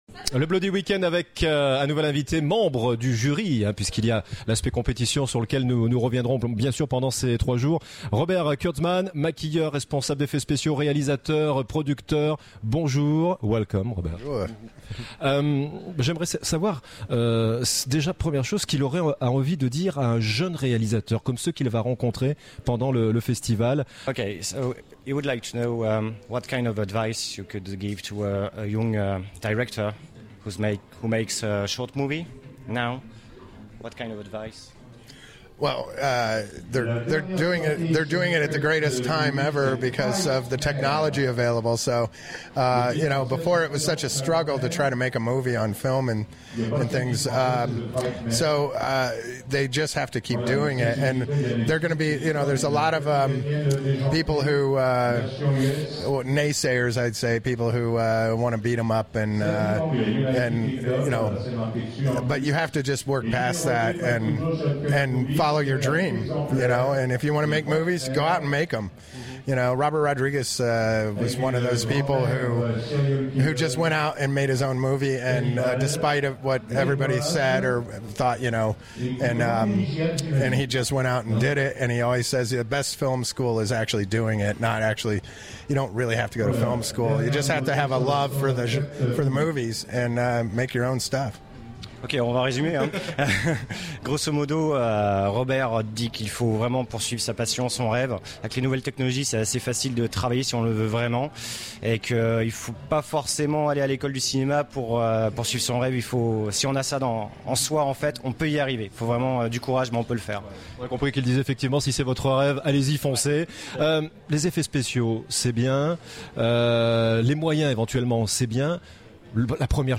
Interview de Robert Kurtzman en séance de dédicaces au Bloody Week End !